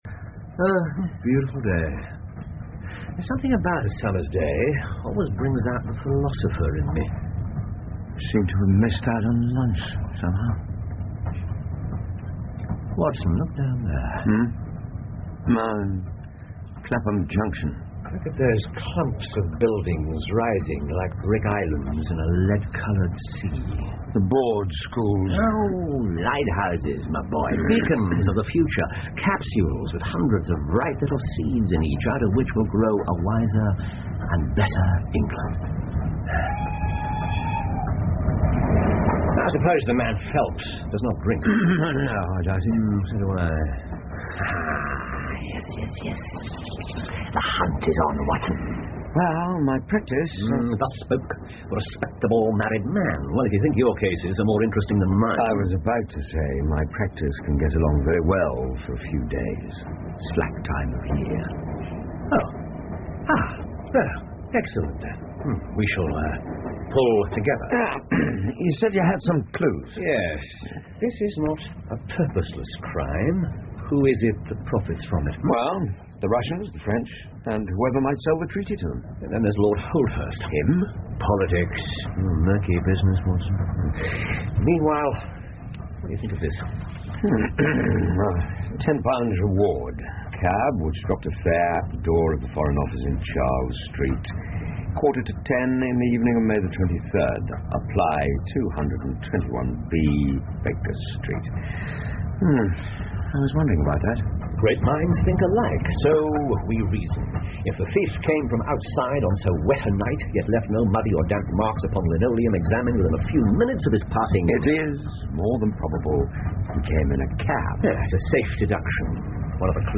福尔摩斯广播剧 The Naval Treaty 5 听力文件下载—在线英语听力室